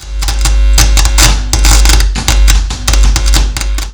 Schlechte Steckverbindung
So klingt eine schlechte Steckverbindung am Eingang eines Verstärkers. Es kommt zu "offenen (nicht geschirmten) Eingängen", die u.a ein "Brumm-Geräusch" wahrnehmen lassen können, welches durch ein 50Hz-230V-Wechselstrom Versorgungsnetz herbeigerufen werden könnte. Auch nur die Berührung (mit Finger) der Signalleitung kann dieses Brummen hervorrufen (Mensch funktioniert als Antenne).